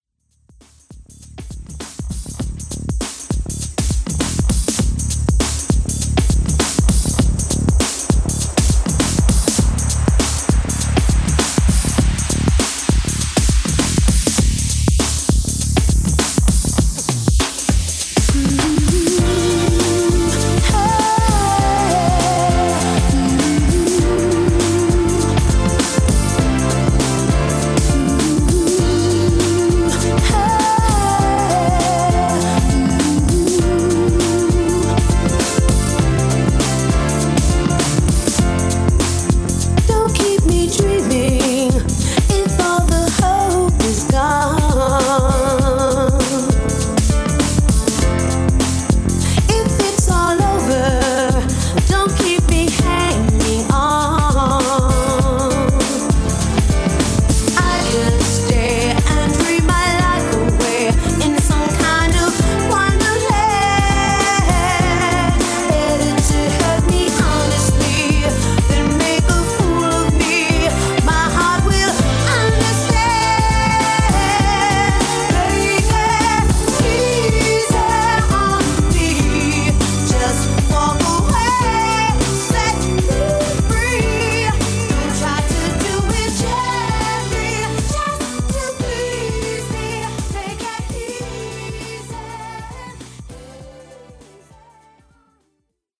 Genre: 2000's
Dirty BPM: 126 Time